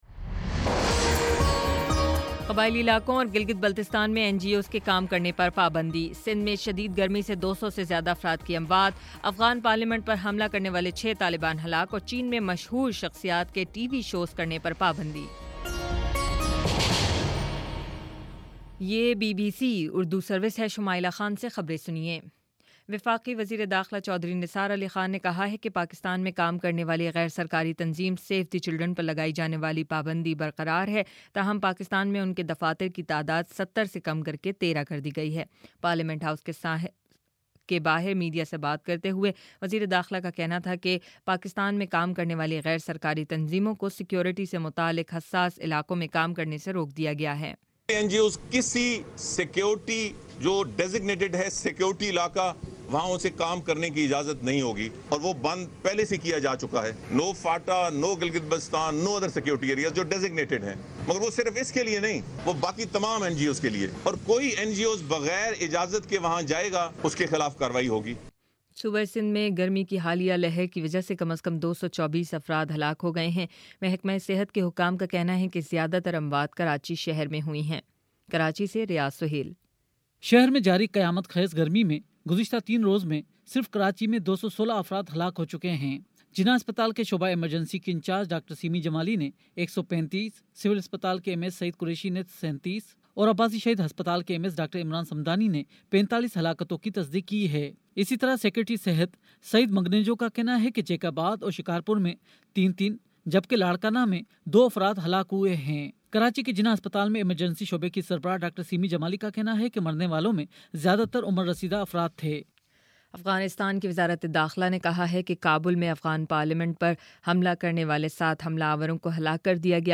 جون 22: شام سات بجے کا نیوز بُلیٹن